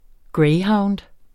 Udtale [ ˈgɹεjˌhɑwnd ]